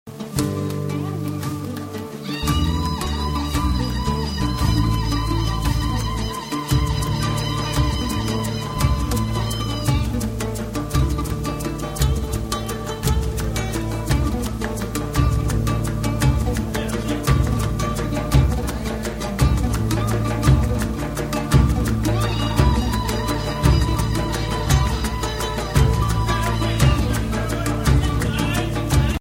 The Off-Beat from Cairo